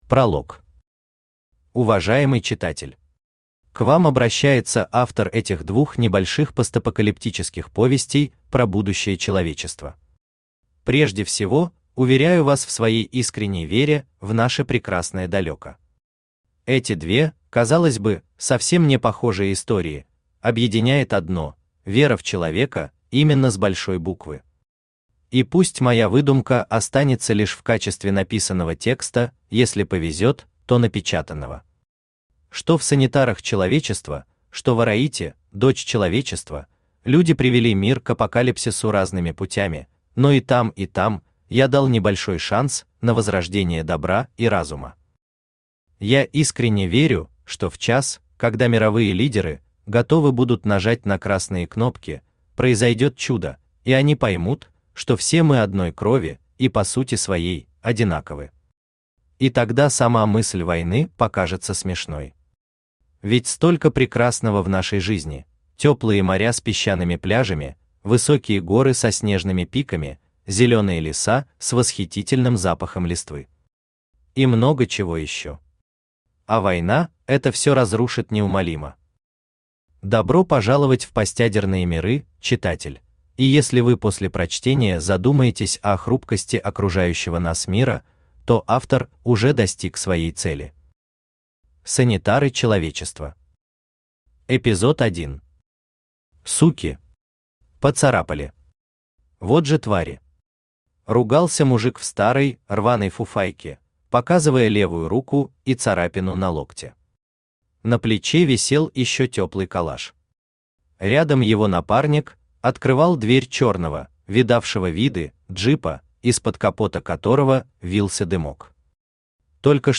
Аудиокнига Завтра будет Апокалипсис | Библиотека аудиокниг
Aудиокнига Завтра будет Апокалипсис Автор Дионмарк Читает аудиокнигу Авточтец ЛитРес.